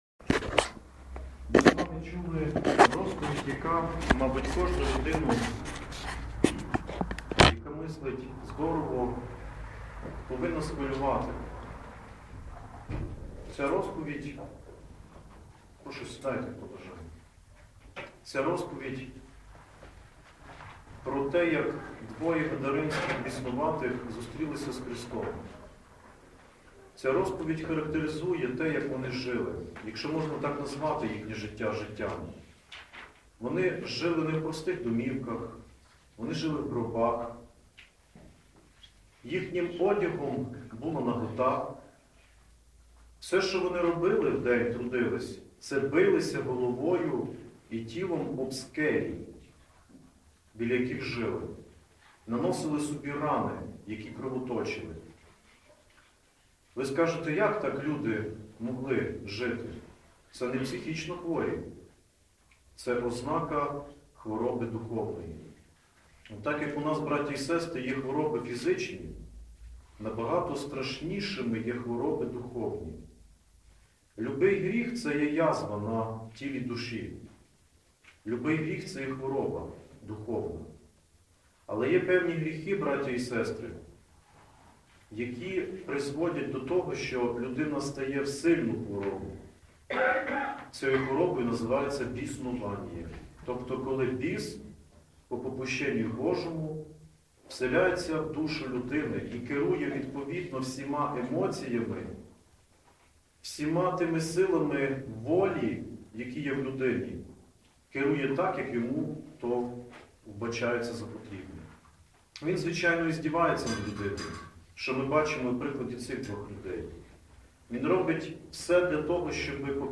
Проповідь у Неділю 5-ту після Пятидесятниці – Храм Святителя Іоанна Шанхайського і Сан-Франциського м. Ужгорода